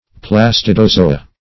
Search Result for " plastidozoa" : The Collaborative International Dictionary of English v.0.48: Plastidozoa \Plas`ti*do*zo"a\, n. pl.